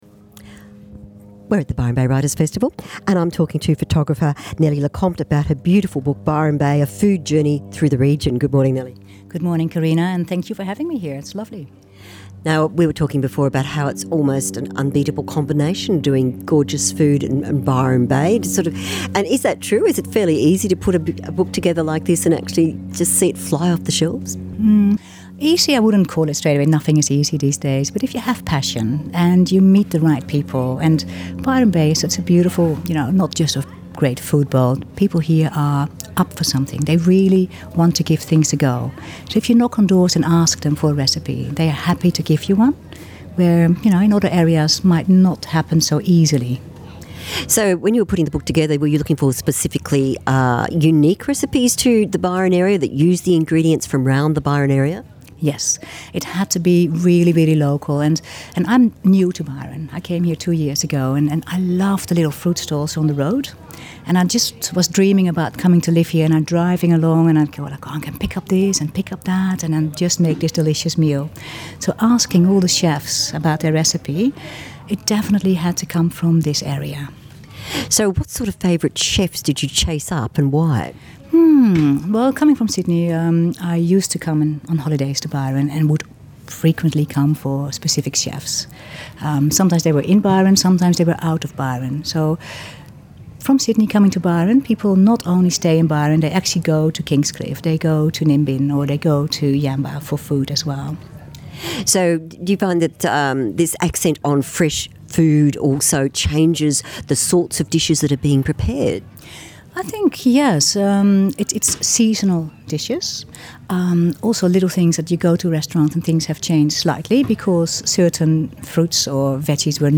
Books and Authors - live interviews, Cooking and food
Recorded at Byron  Writers Festival 2015